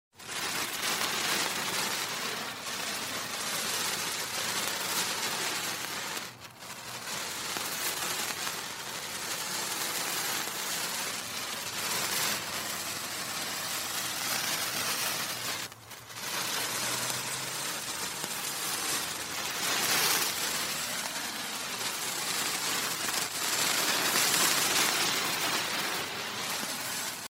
Звуки бенгальских огней
Бенгальский огонь